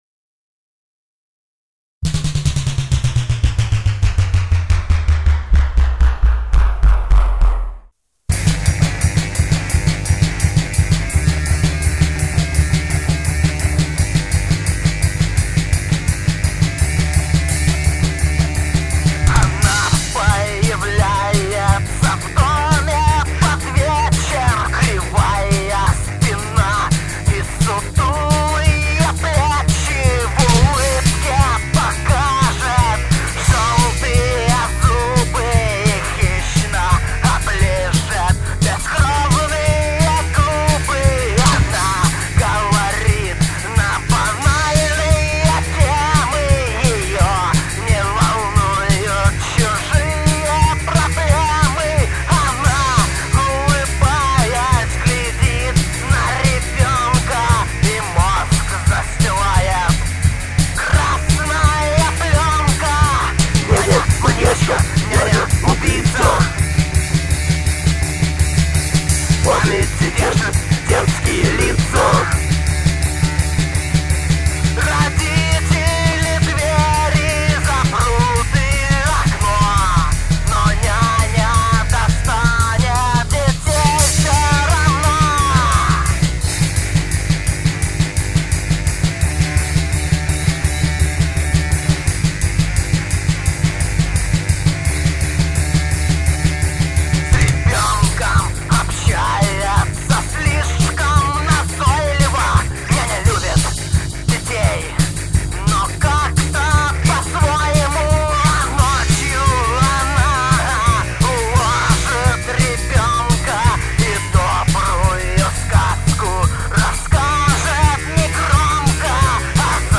Гитары, бас, вокал
Барабаны, бэк-вокал